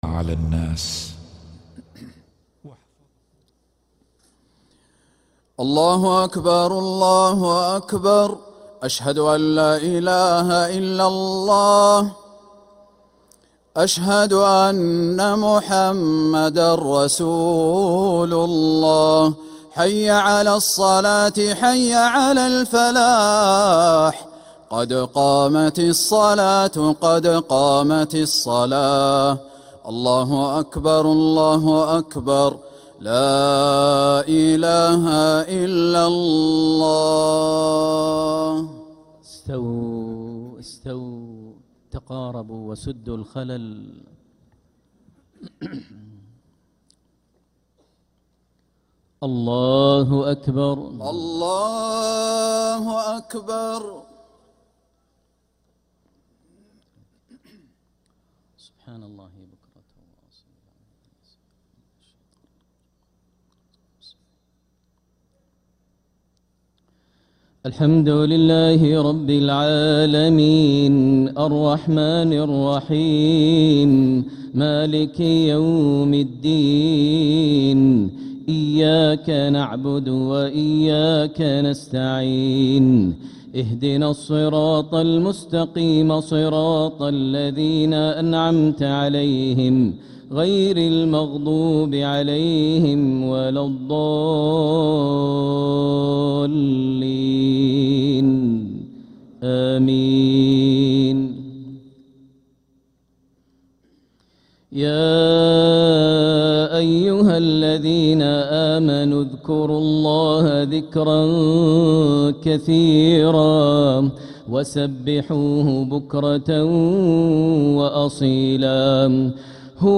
Makkah Maghrib - 24th April 2026